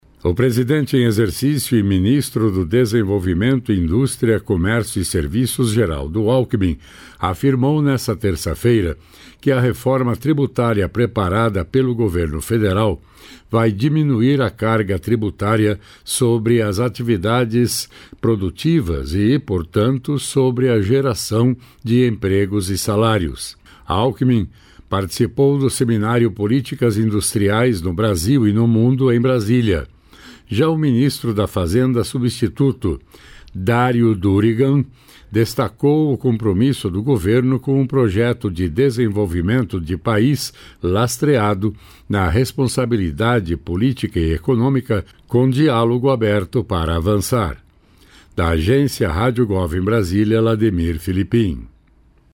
Alckmin participou, do seminário Políticas Industriais no Brasil e no Mundo, em Brasília.